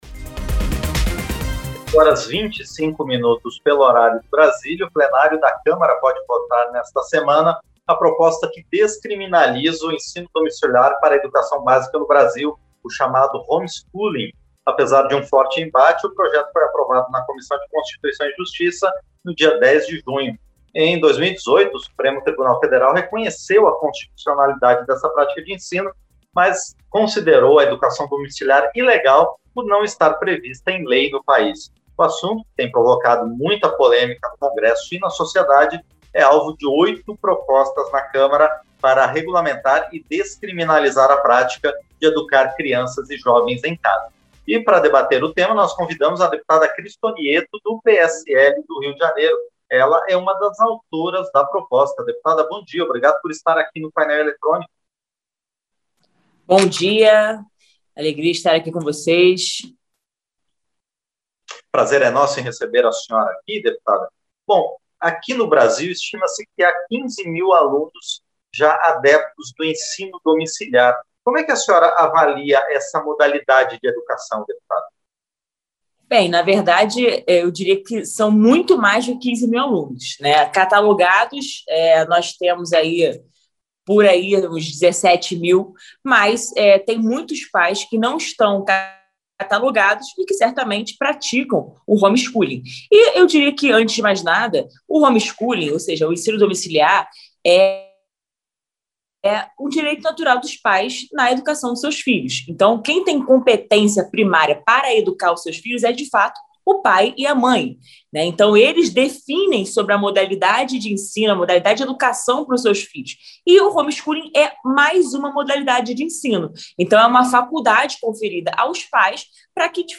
Entrevista - Dep. Chris Tonietto (PSL-RJ)